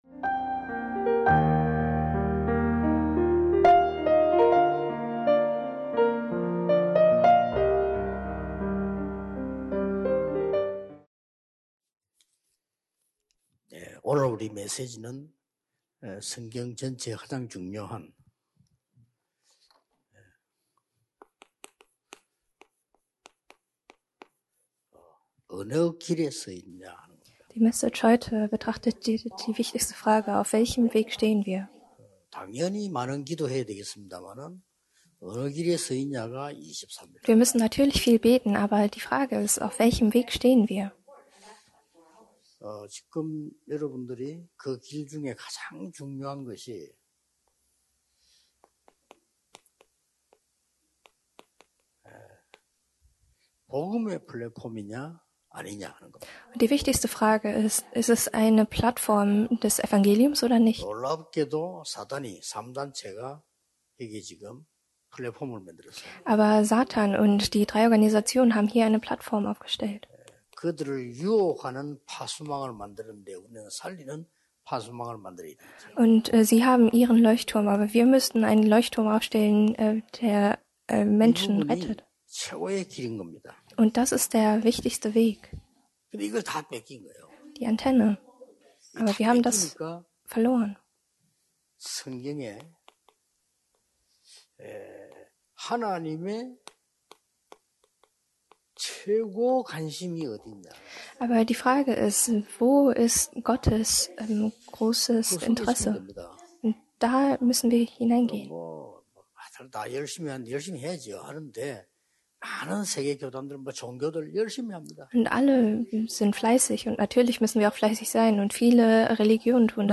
Kernpredigt (8) – Der einzige Weg die Nephilim aufzuhalten (Gen 6:4-5) – WEEA